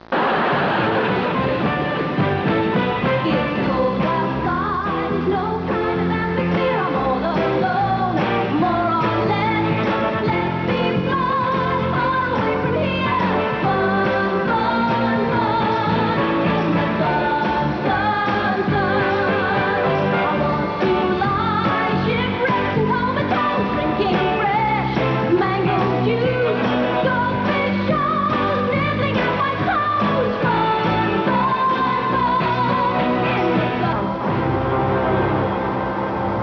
Oh, and here's a medium-quality sound-bite of the
theme song.